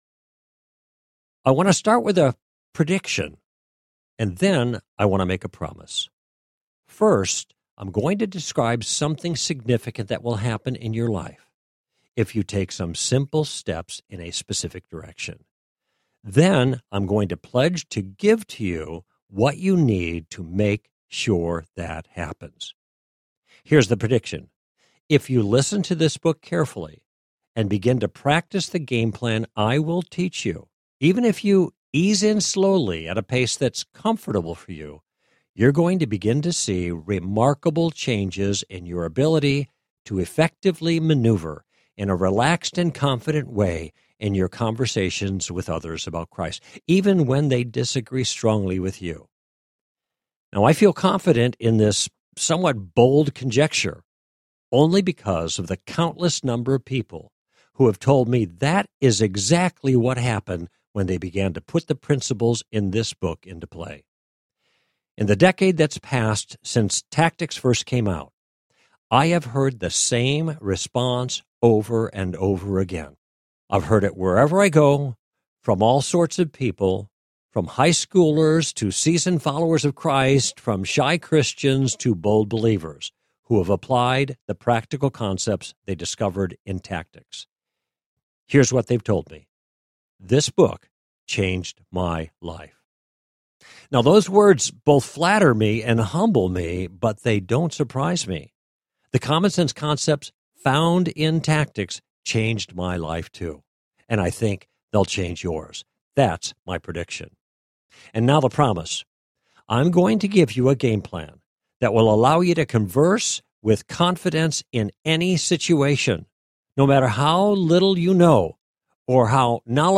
Tactics, 10th Anniversary Edition Audiobook
Narrator